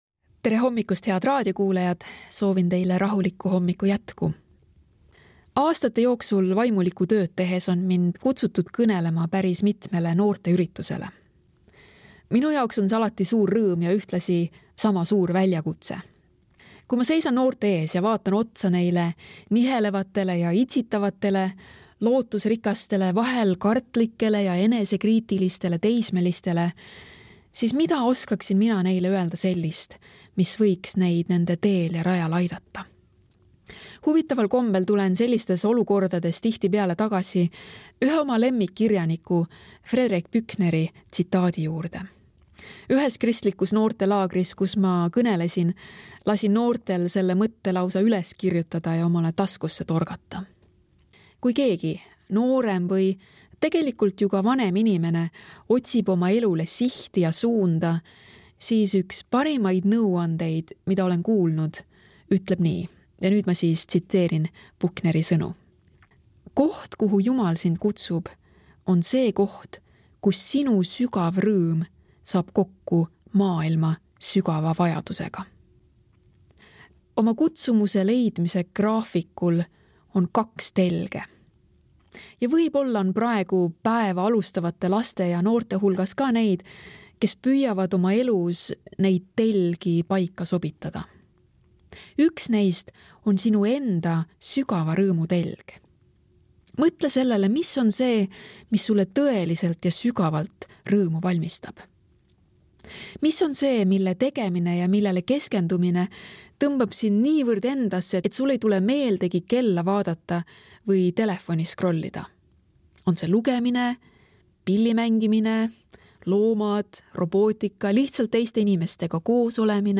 hommikumõtisklus ERR-s 14.11.2025
Hommikupalvused